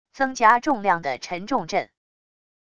增加重量的沉重阵wav音频